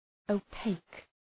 Προφορά
{əʋ’peık}